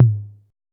808TOM1 LO.wav